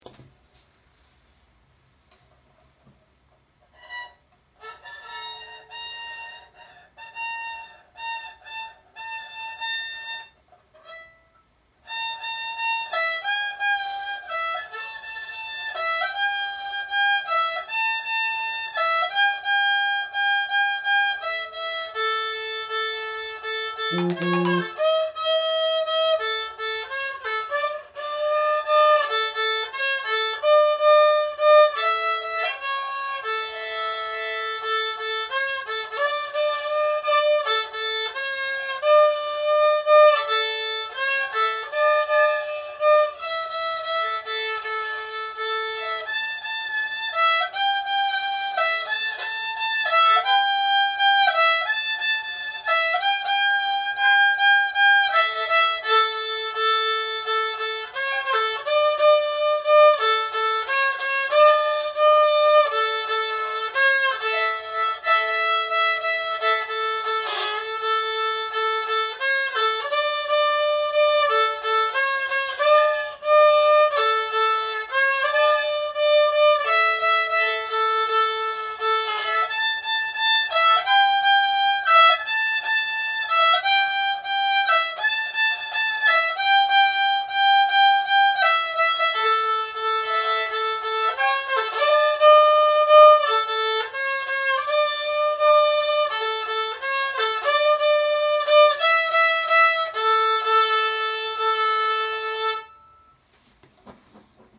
First time fiddle.
Please, please be kind - this me playing around with my son's fiddle